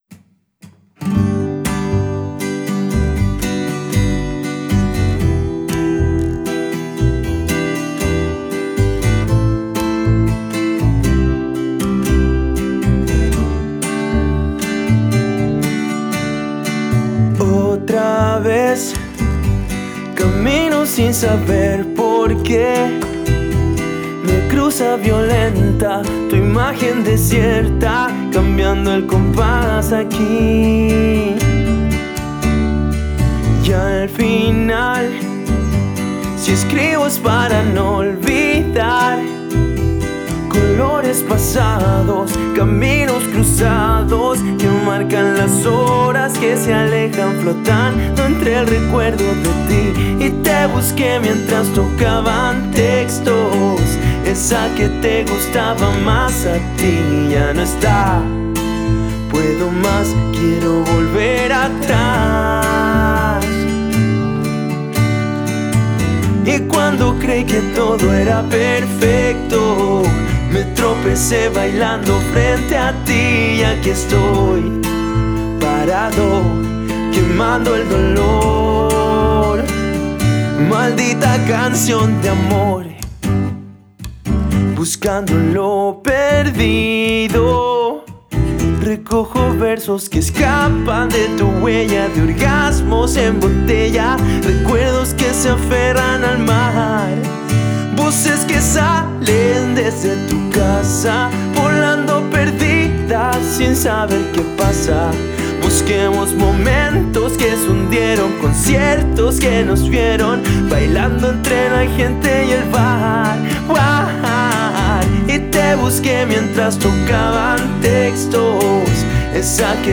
voz y guitarra de la agrupación.
en teclados
en bajo